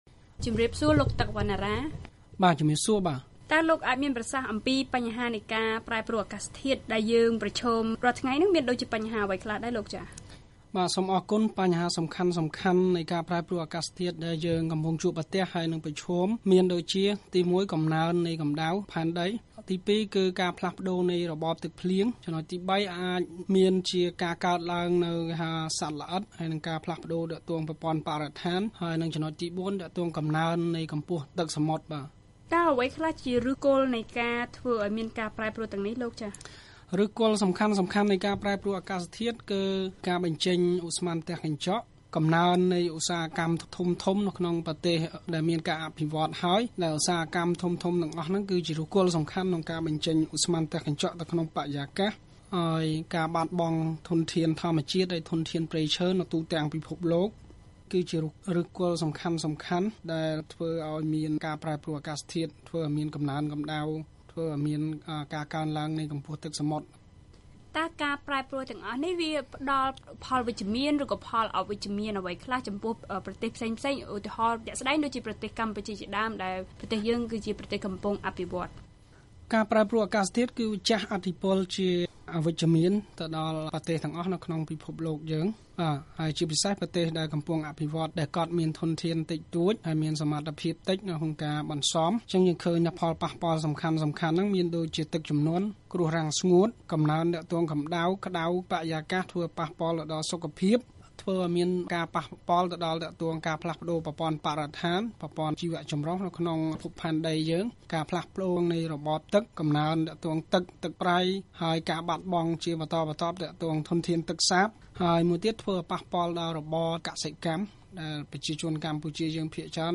បទសម្ភាសន៍ VOA៖ កម្ពុជាអាចទទួលបានថវិកាសម្រាប់ជួយដល់ការកាត់បន្ថយកំណើនកំដៅផែនដីពិភពលោក